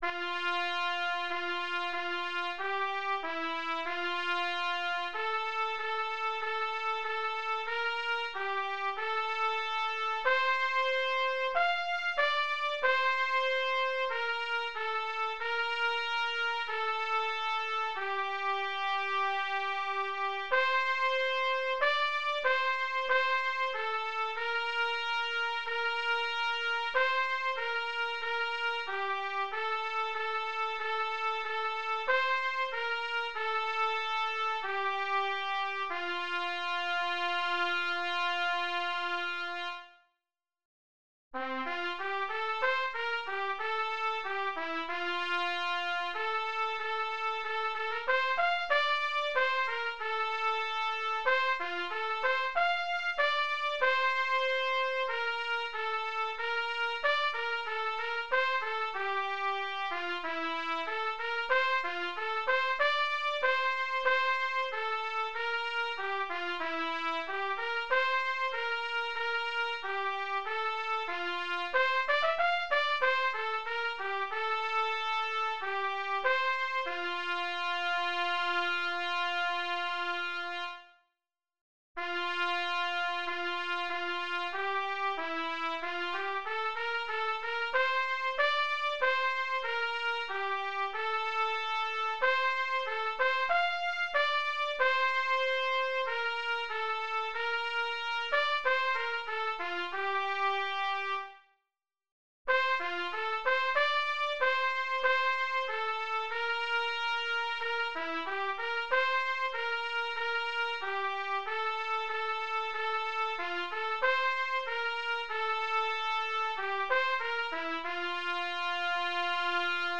DIGITAL SHEET MUSIC - TRUMPET SOLO
Sacred Music, Hymns, Unaccompanied Solo